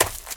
High Quality Footsteps
STEPS Leaves, Run 14.wav